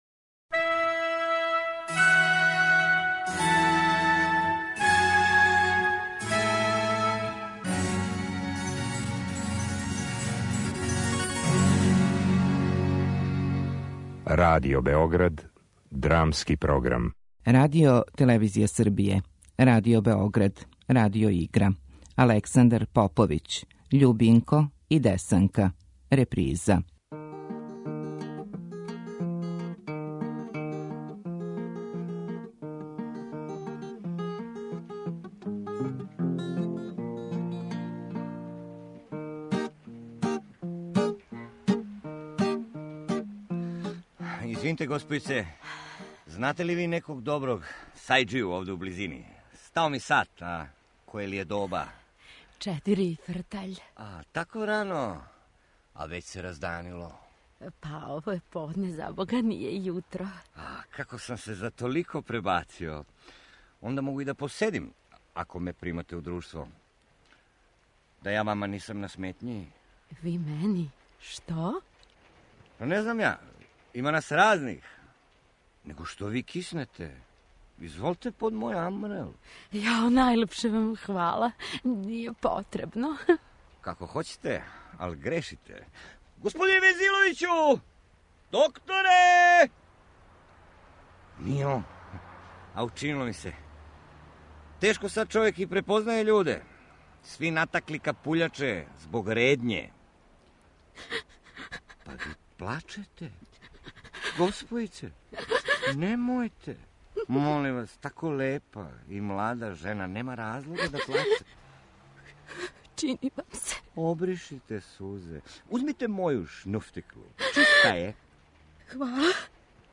Радио-игра